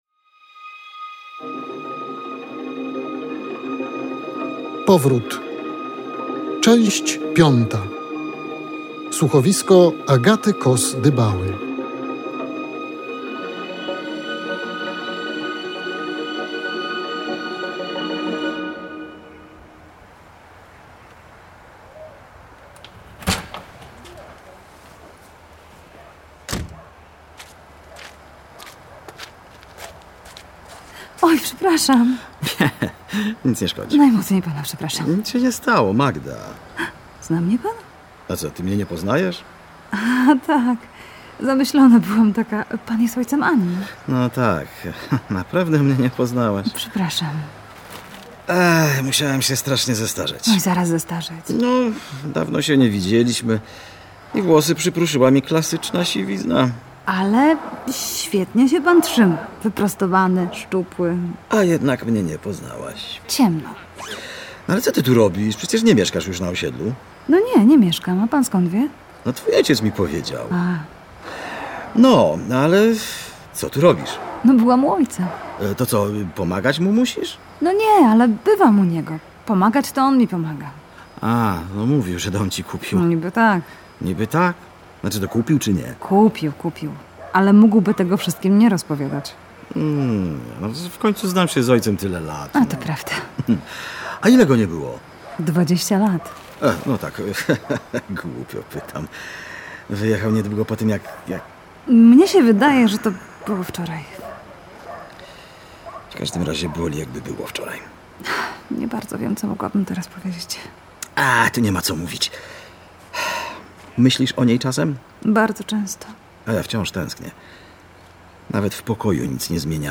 O powrocie prawie wszystko i jeszcze więcej w piątej części słuchowiska o tytule - nomen omen - "Powrót".